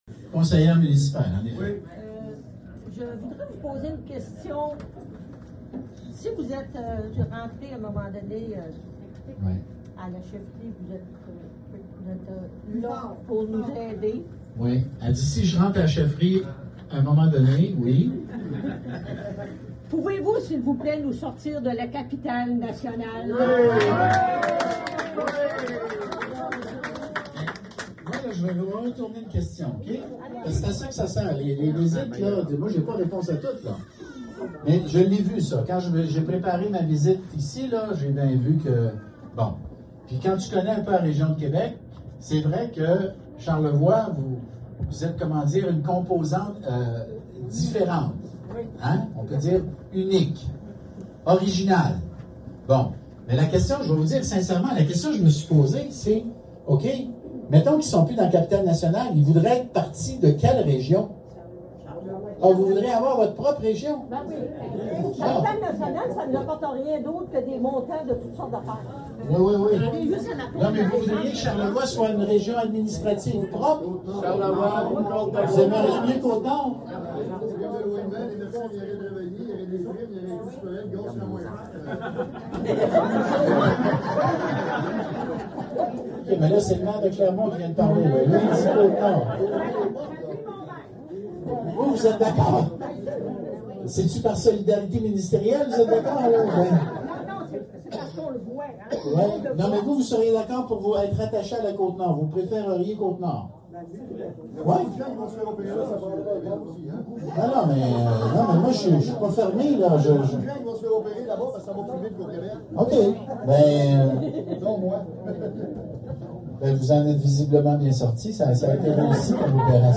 De passage dans la région pour rencontrer les citoyens, Bernard Drainville, candidat à l’investiture de la Coalition Avenir Québec (CAQ), a réitéré son engagement envers les régions lors d’un arrêt au resto-pub Belles et Bum.